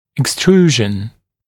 [ɪks’truːʒn] [ek-] [икс’тру:жн] [эк-] экструзия, выдвижение